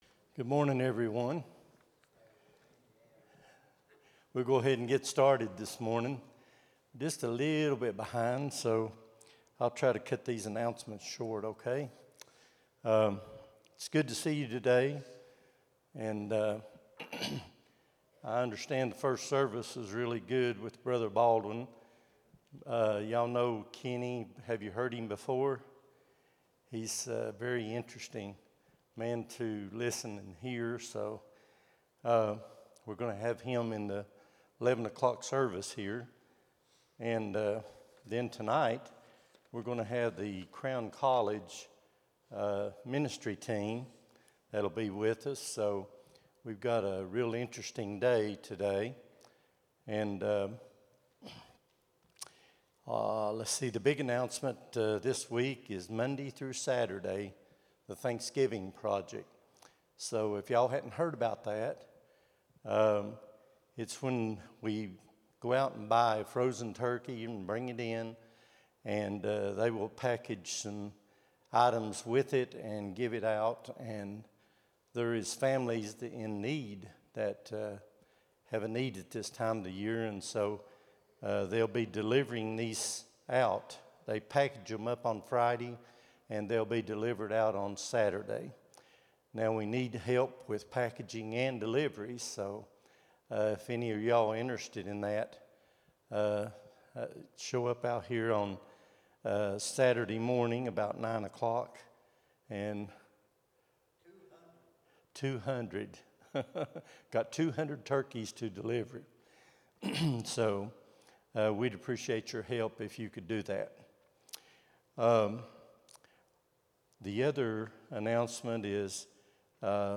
11-16-25 Sunday School | Buffalo Ridge Baptist Church